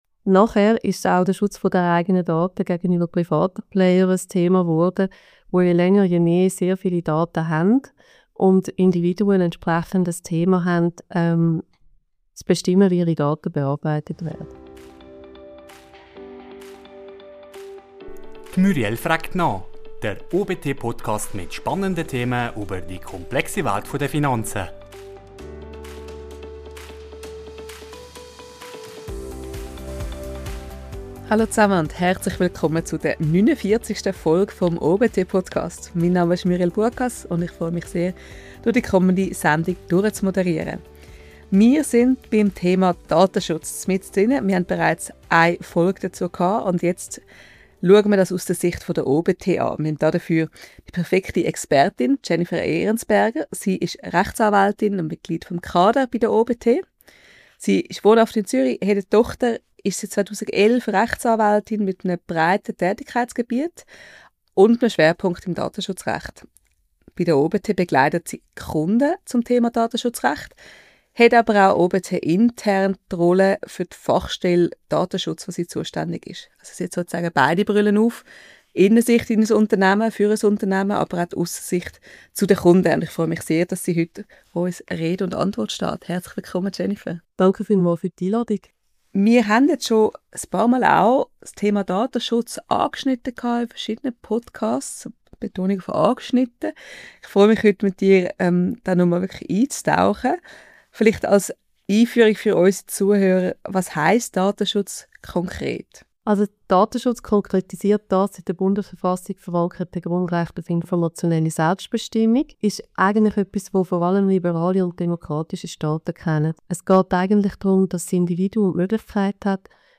Podcast-Studio